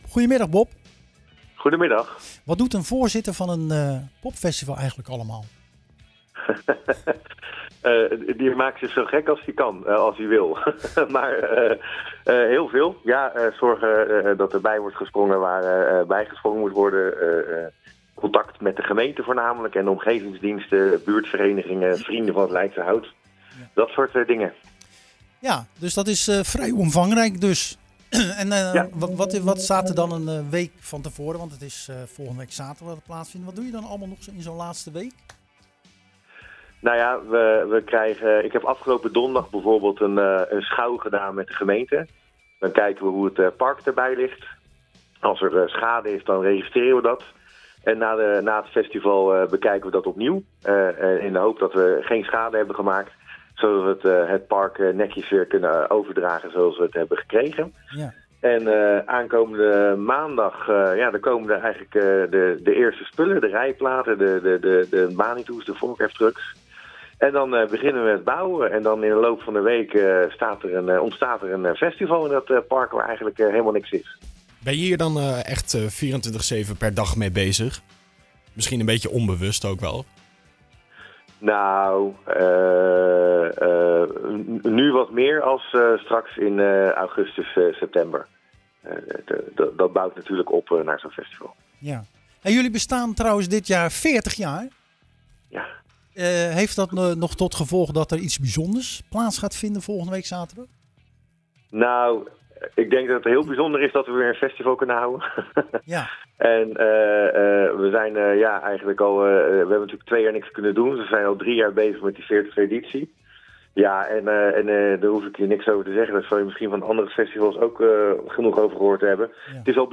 Tijdens het programma Zwaardvis belde we met voorzitter